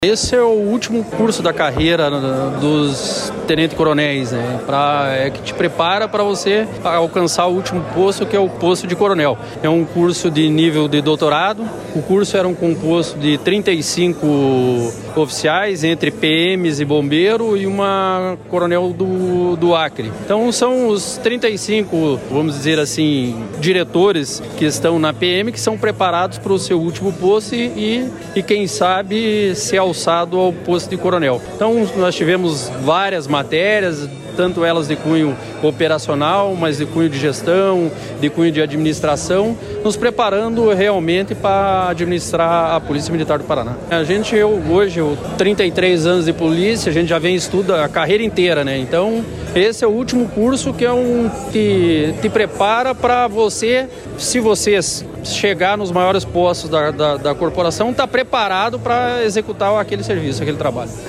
Sonora do chefe da Casa Militar, tenente-coronel Sérgio Vieira, sobre a formatura de novos oficiais da PM e do Corpo de Bombeiros